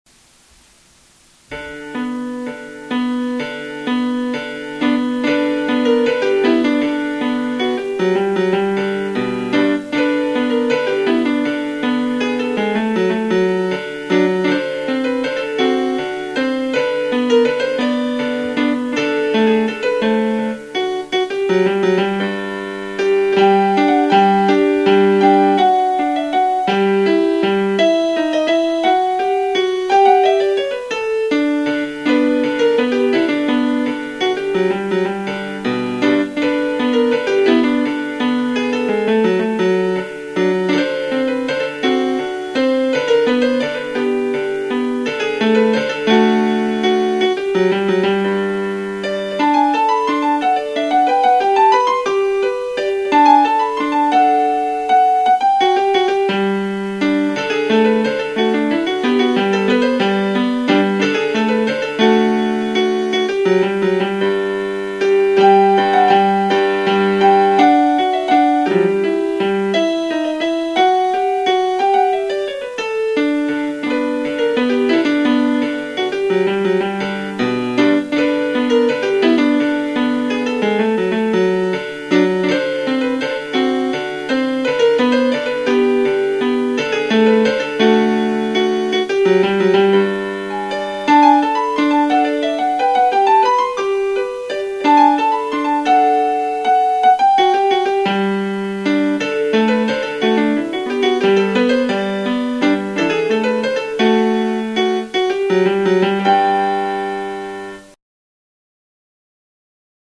Here's a little piano composition of mine which is circusy ....
>> carousel stride. (at least i it sounded sorta circusy to me so that's why i named it that)
zany